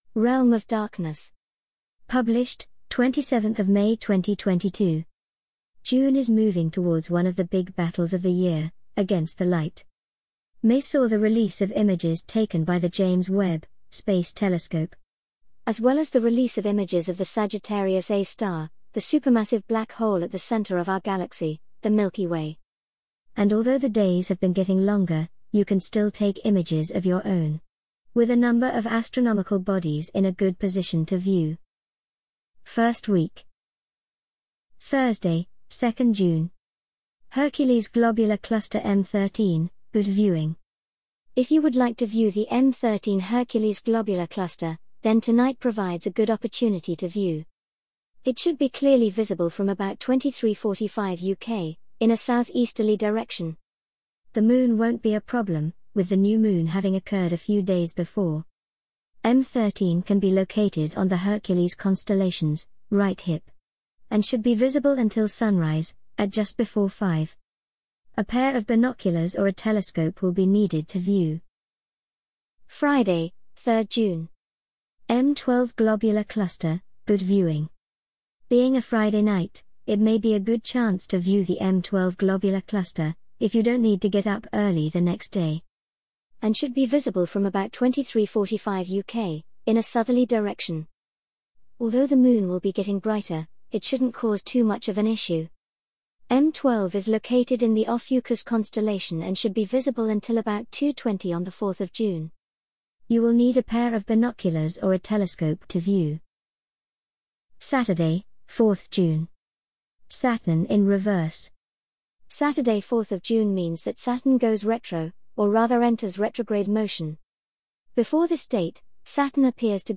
An audio reading of the Realm of Darkness June 2022 Article